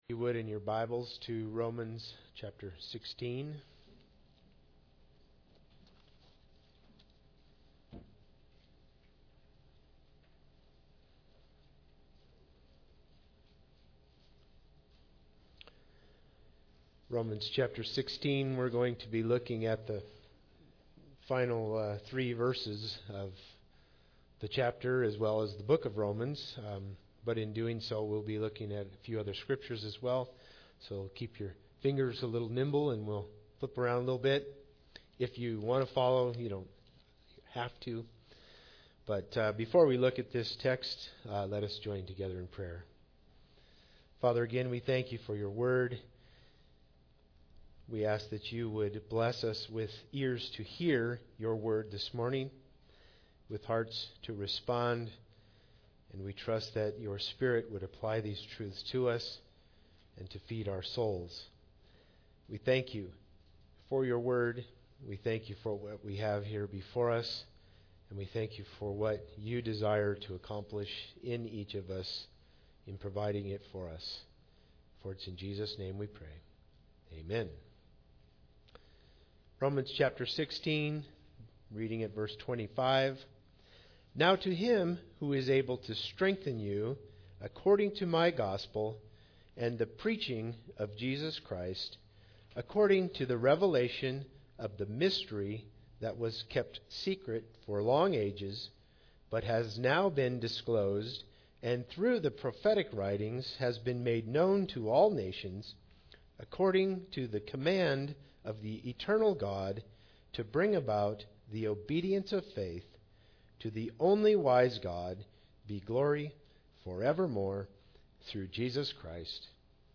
Romans 16:25-27 Service Type: Sunday Service Bible Text